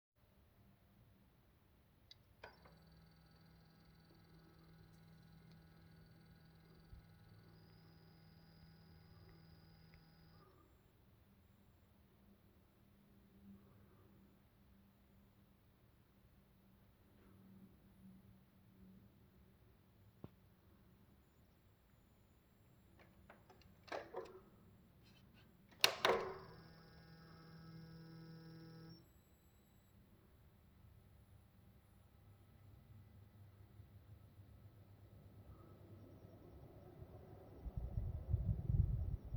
- Début : courant coupé
- 3s : mise en courant
- 26s : allumage unité.
A noté qu'après la mise en courant une led clignote et le son parasite a 2 tonalités suivant le clignotement de la led.